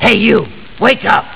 Male Voice -